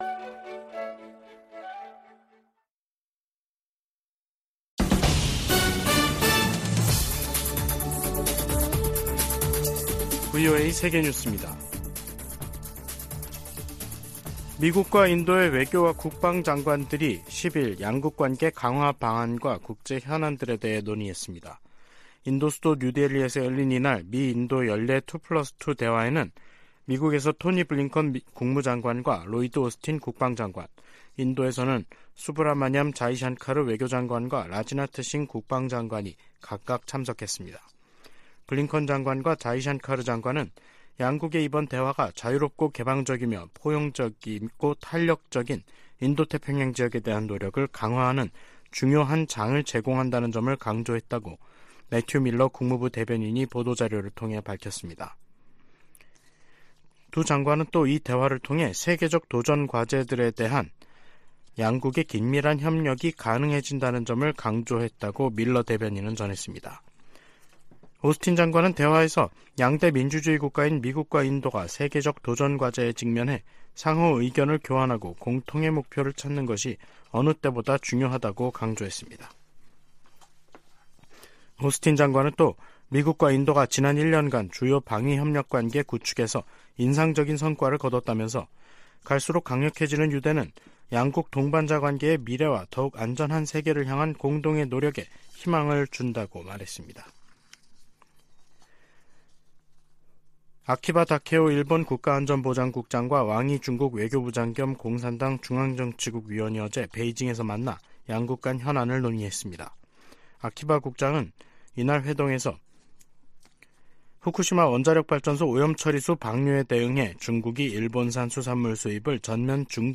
VOA 한국어 간판 뉴스 프로그램 '뉴스 투데이', 2023년 11월 11일 3부 방송입니다. 토니 블링컨 미국 국무장관이 윤석열 한국 대통령과 만나 북한과 우크라이나, 가자지구 문제 등 양국 현안을 논의했다고 국무부가 밝혔습니다. 미국 정부가 북한을 비호하는 러시아의 태도를 비판하면서 북한 정권에 분명한 메시지를 전할 것을 촉구했습니다.